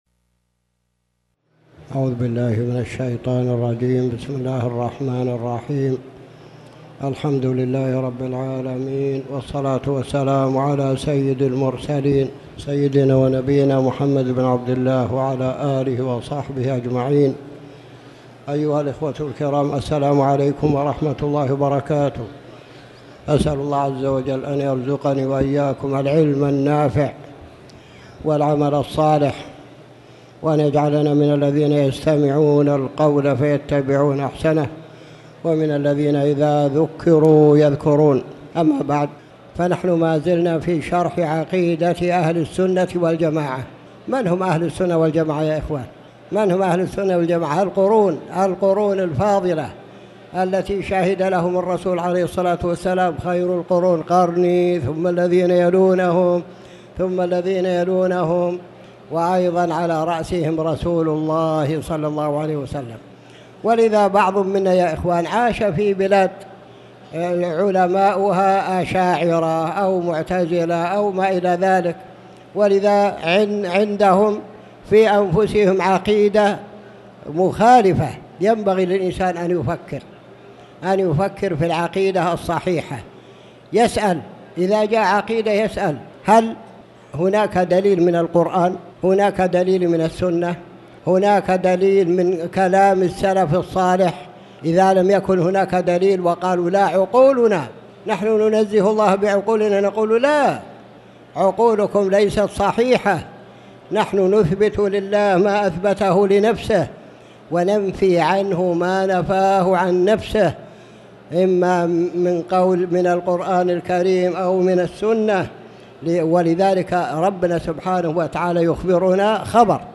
تاريخ النشر ١٧ صفر ١٤٣٩ هـ المكان: المسجد الحرام الشيخ